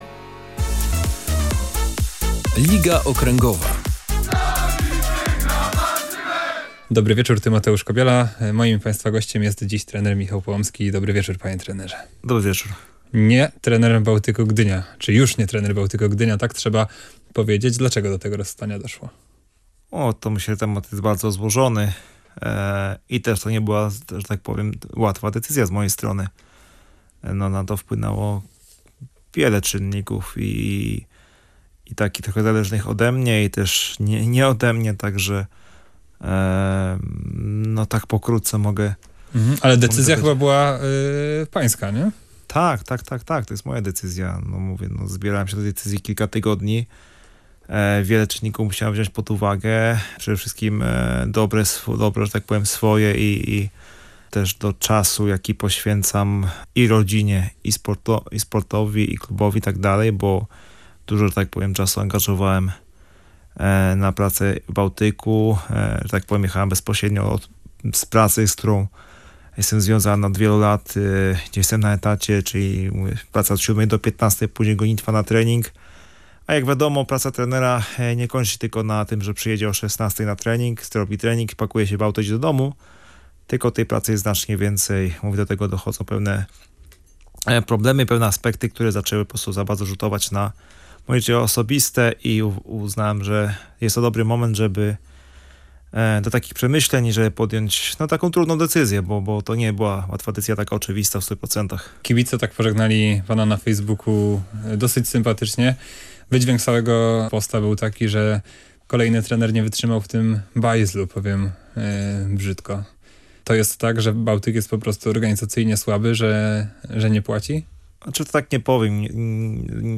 O powodach rozstania z Bałtykiem rozmawialiśmy w audycji „Liga Okręgowa”.